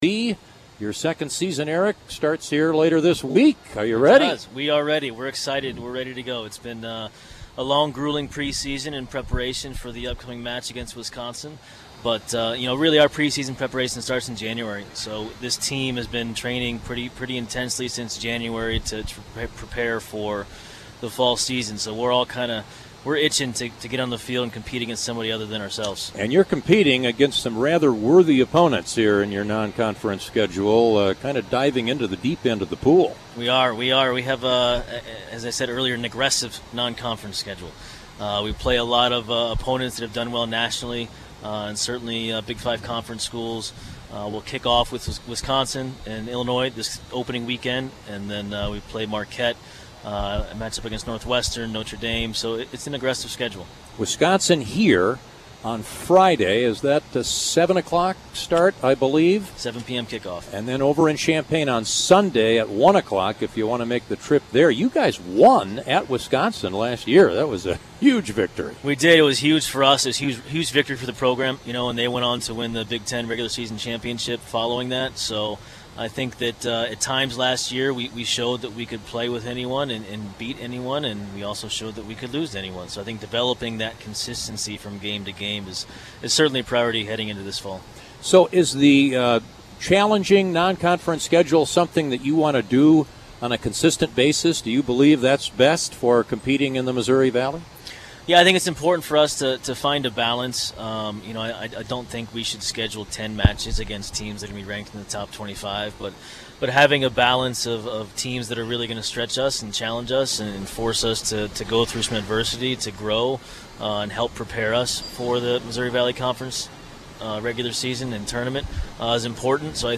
from Redbird Preview Day and Hog Roast